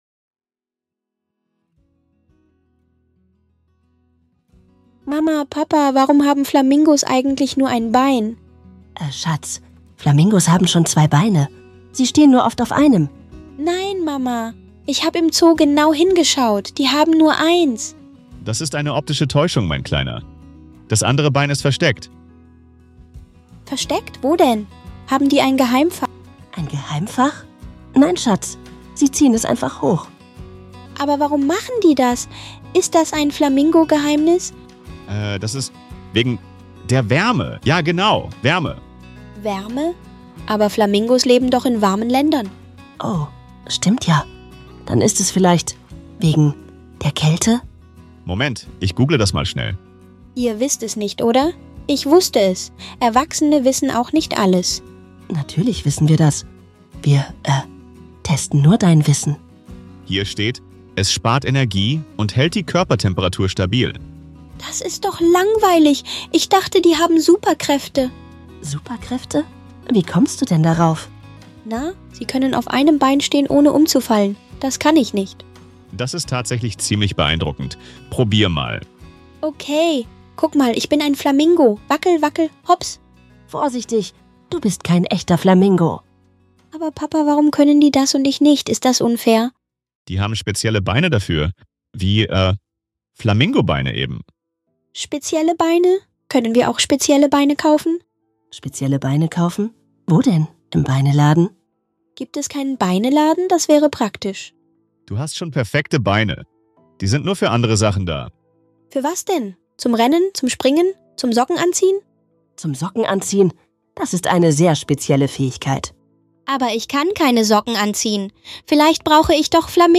Frage über Flamingos stellt? Mama und Papa geraten in eine lustige
Wissenslücken-Krise! In diesem urkomischen Gespräch entdeckt eine